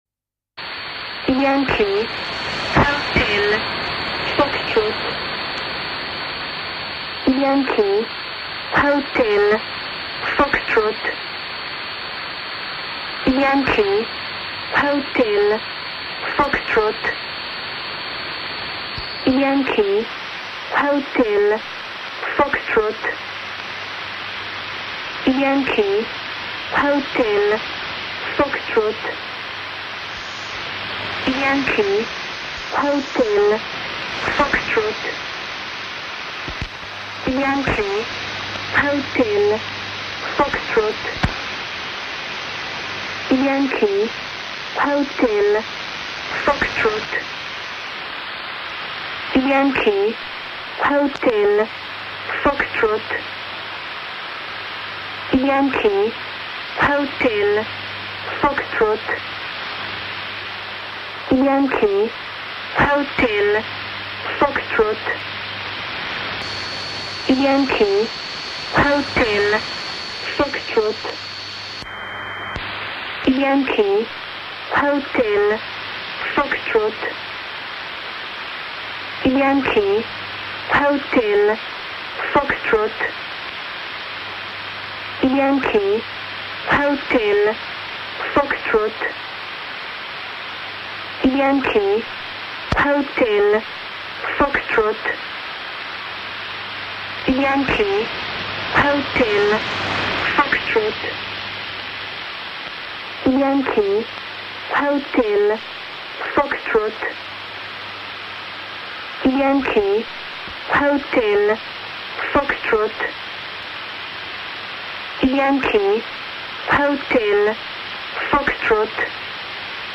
Any short wave radio enthusiast will eventually bump into stations which continuously transmit spooky stuff like this.